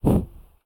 sum_airship_fire.ogg